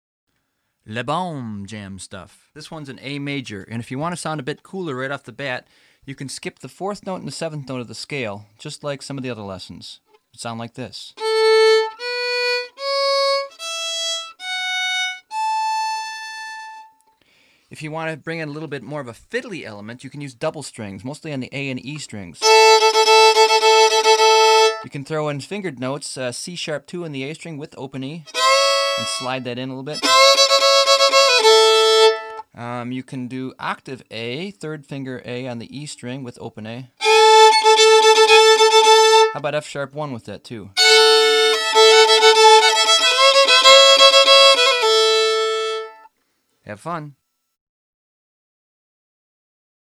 • Escala: La Mayor
• Carácter: brillante, feliz, dulce.
Ejemplo de improvisación